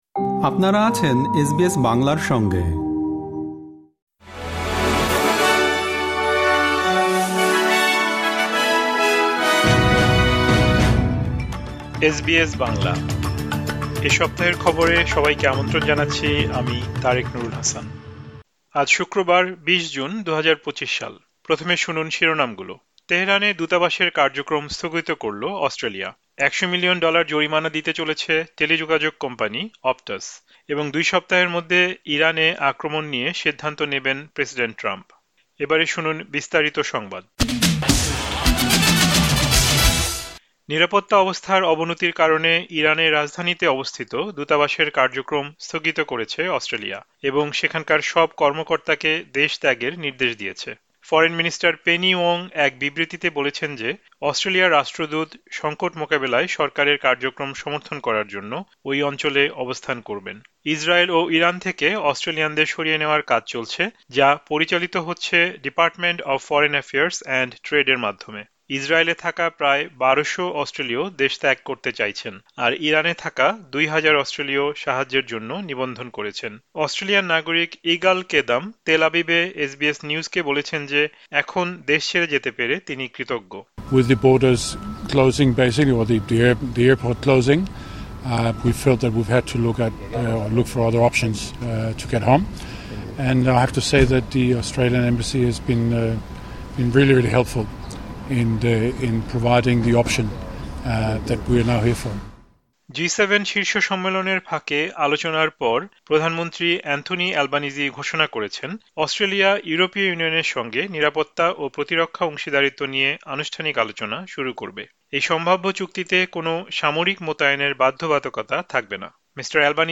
এ সপ্তাহের খবর